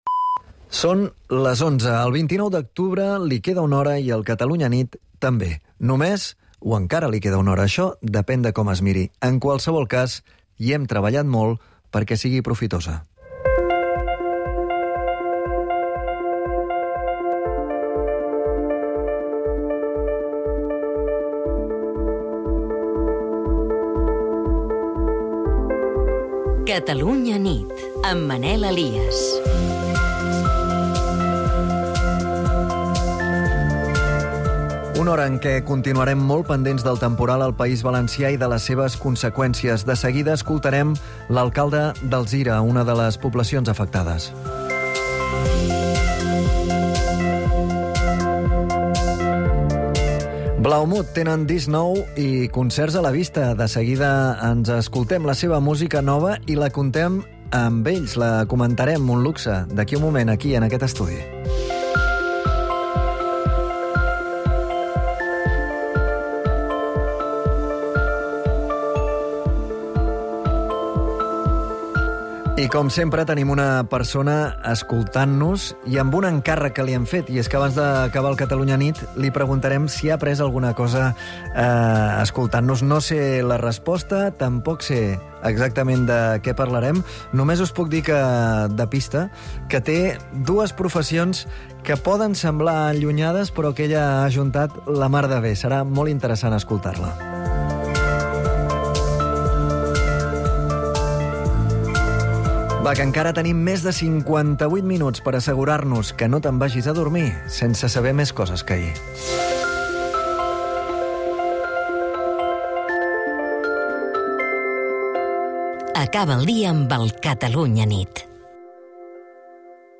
Estem molt pendents del temporal al País Valencià i de les seves conseqüències. Parlem amb l'alcalde d'Alzira, una de les poblacions afectades.